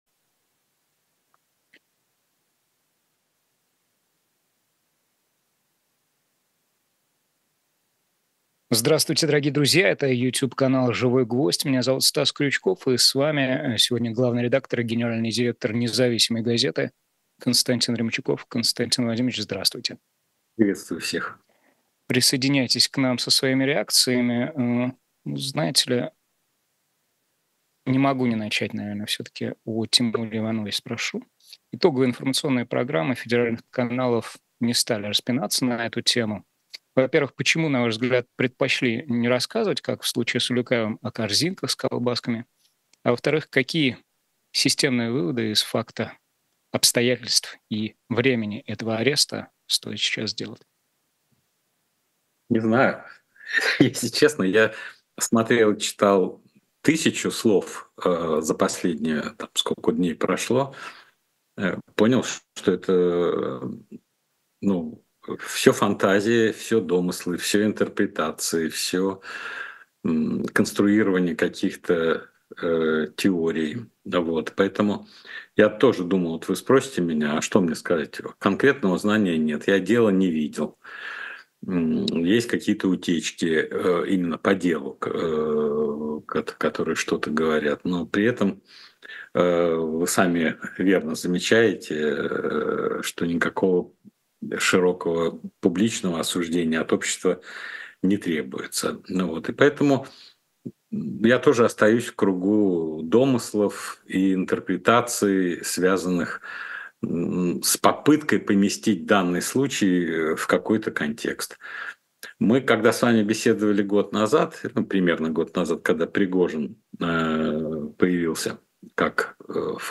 Гость: Константин Ремчуков.